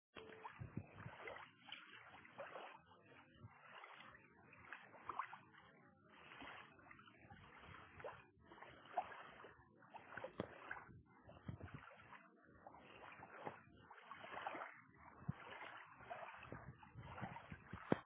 描述：飞溅的水